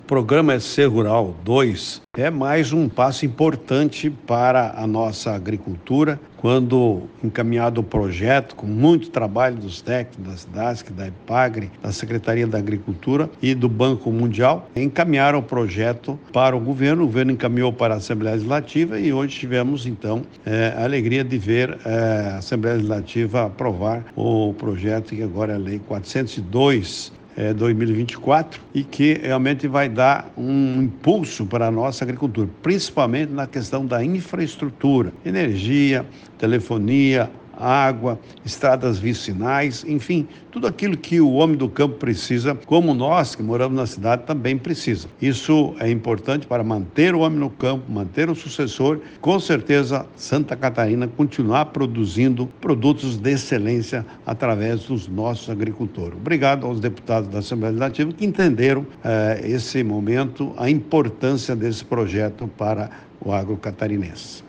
O secretário de Estado da Agricultura e Pecuária, Valdir Colatto, destaca que o SC Rural será fundamental para levar mais desenvolvimento ao campo: